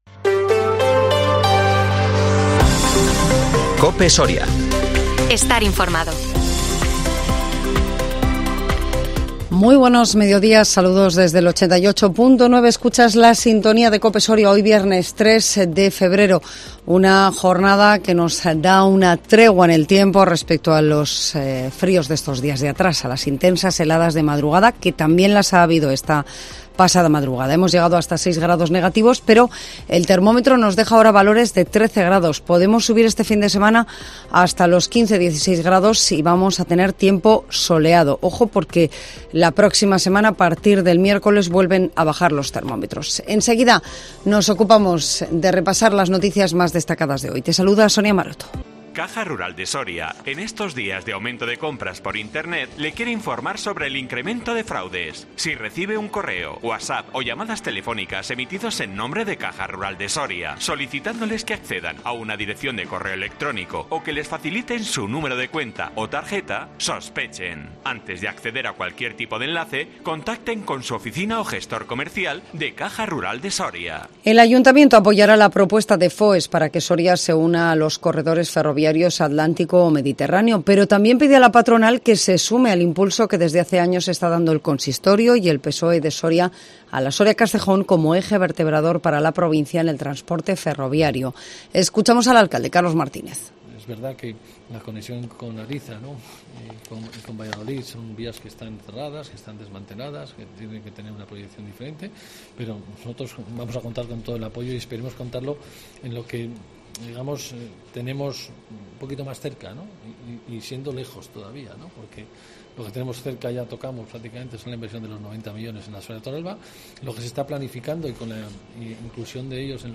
INFORMATIVO MEDIODÍA COPE SORIA 3 FEBRERO 2023